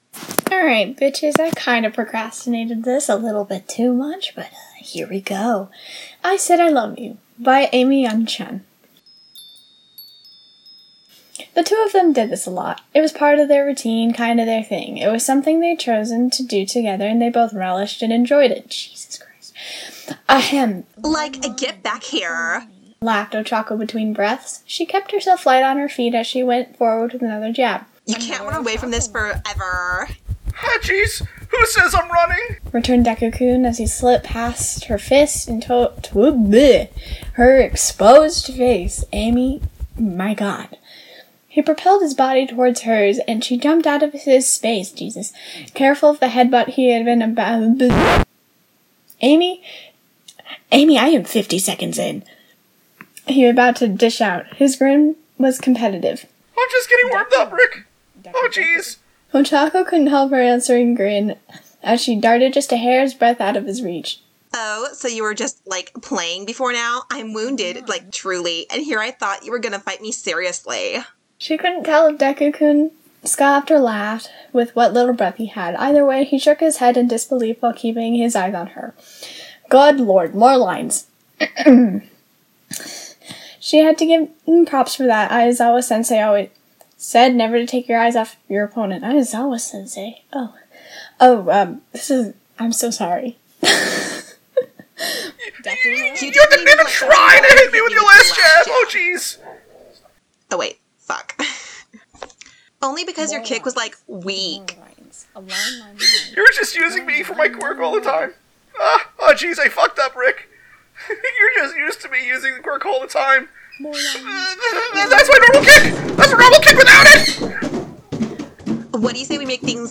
I just tend to forget the parts that hurt," Podfic links Read the original work on Archive of Our Own Listen on Archive of Our Own Notes This is a crack podfic, meaning it's a joke parody of an otherwise not crack fic.